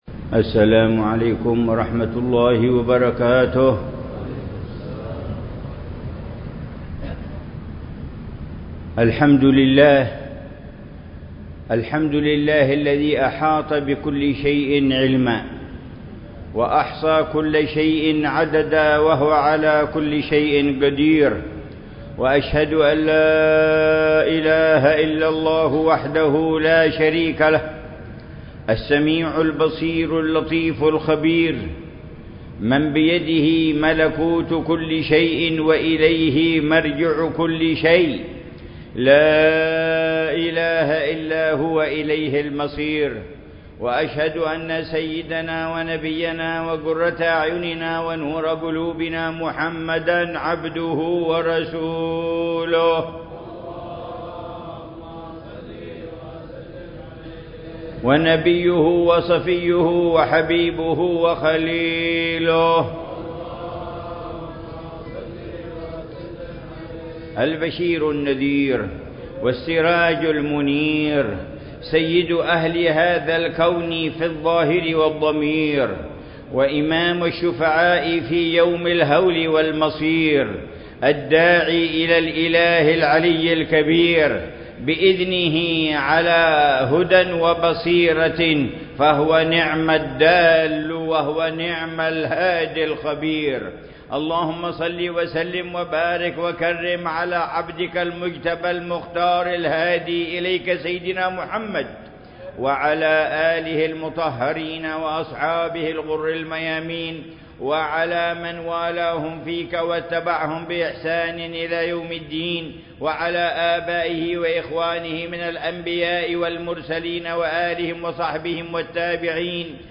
خطبة الجمعة للعلامة الحبيب عمر بن محمد بن حفيظ في جامع التوفيق، بحارة التوفيق، عيديد، مدينة تريم، 19 ذو الحجة 1444 هـ بعنوان: